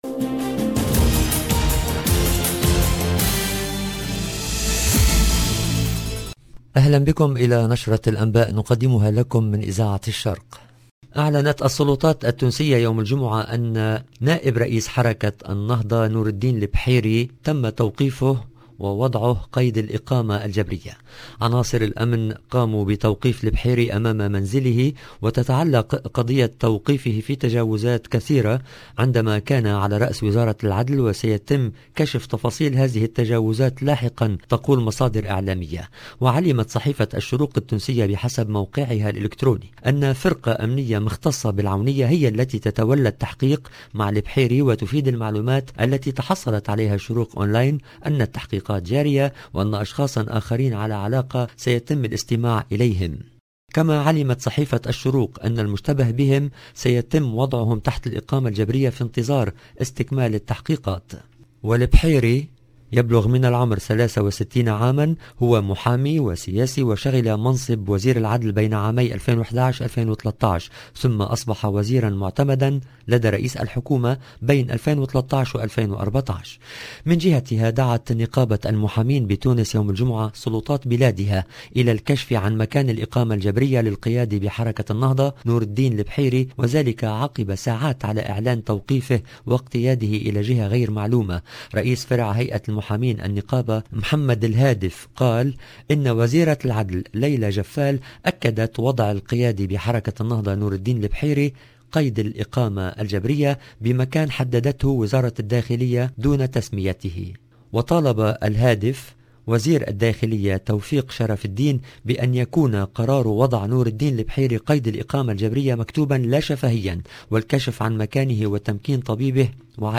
LE JOURNAL EN LANGUE ARABE DU SOIR DU 31/12/21